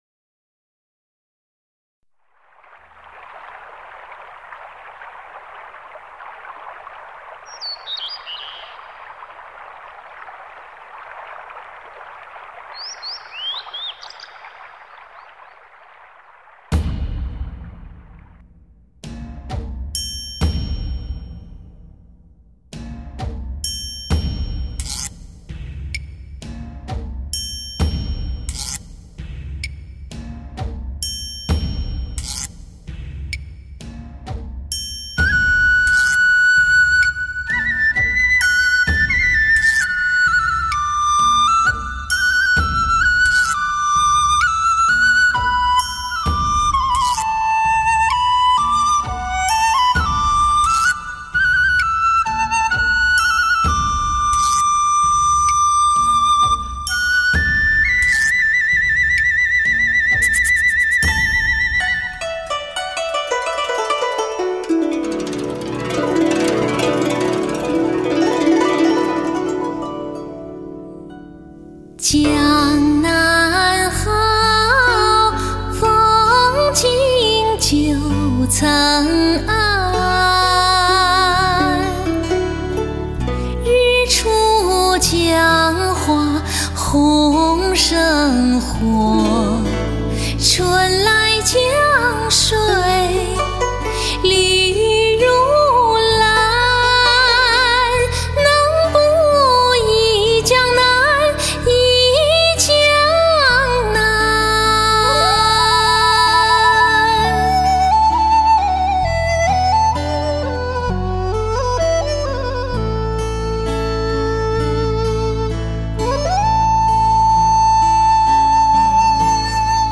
欧洲顶级音乐工作室和制作人精心打造，全部采用膽类真空管进行声音处理，
女声